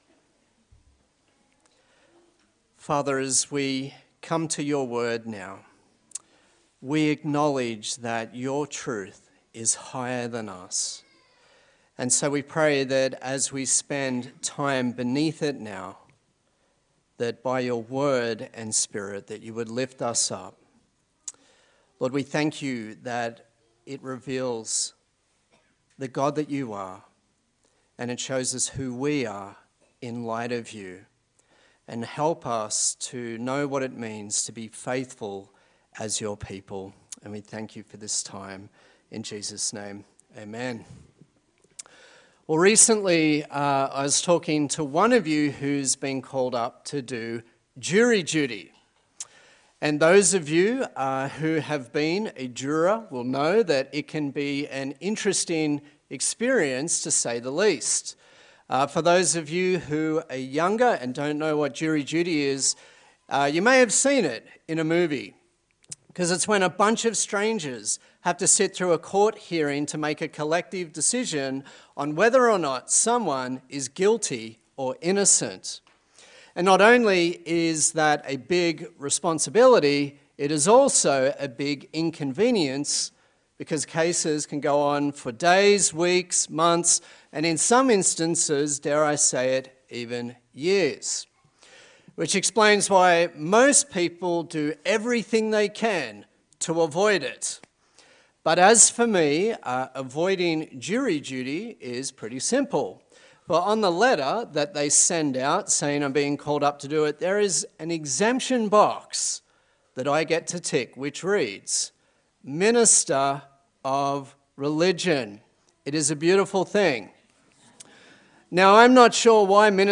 Service Type: Sunday Service A sermon in the series on the book of Acts